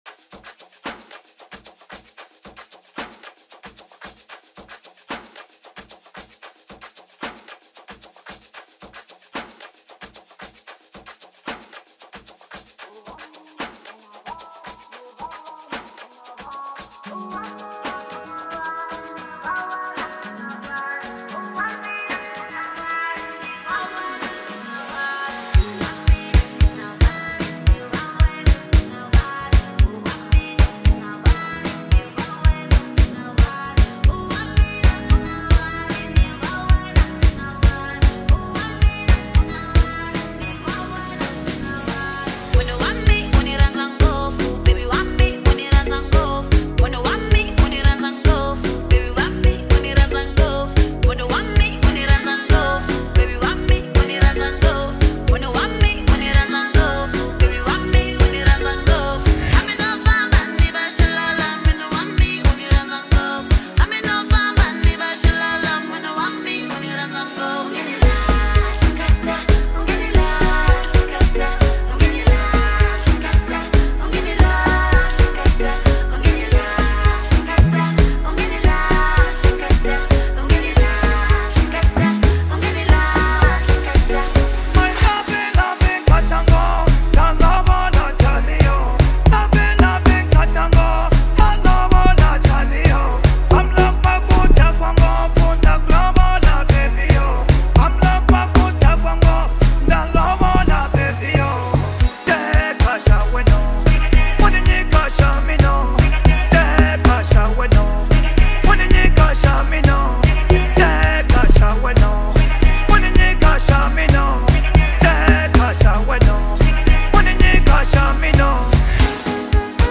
brings rhythm,energy and irresistible vibes
the kind of track that keeps crowds dancing